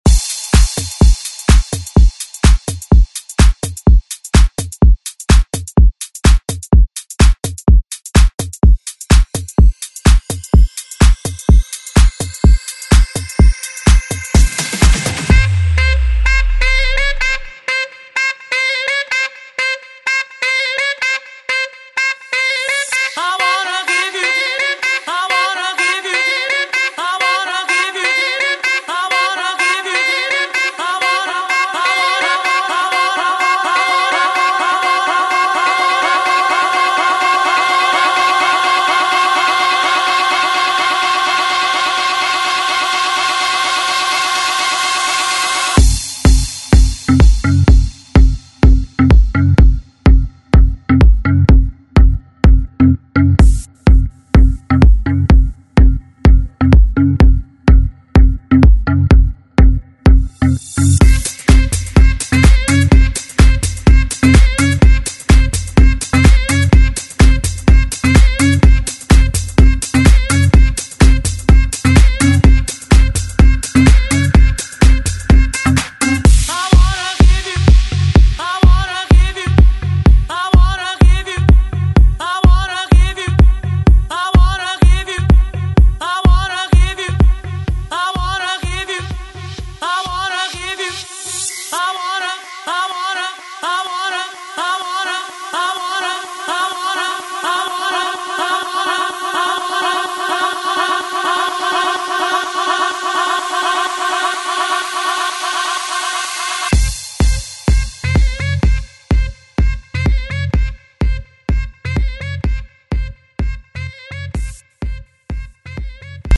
70s Throwback Pop Music
Genres: 70's , RE-DRUM
BPM: 95